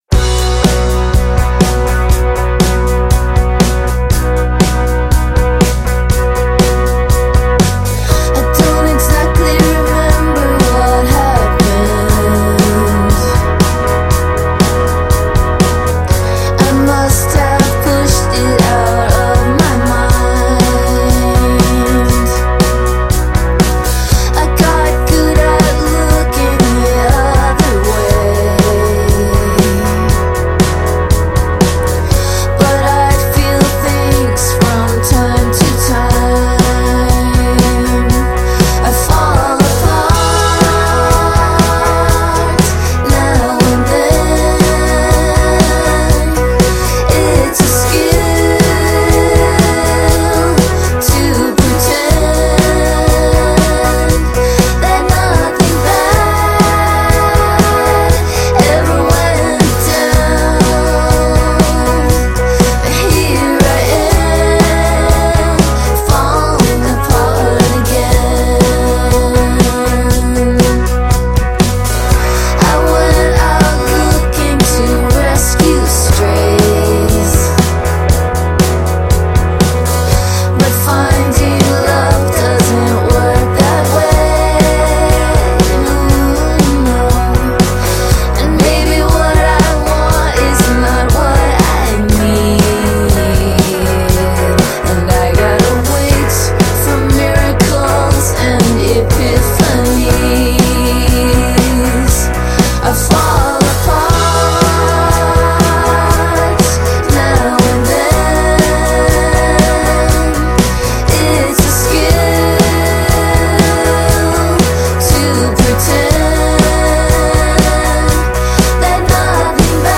типа новой волны, для любителей музыки 1980х
Инди-рок.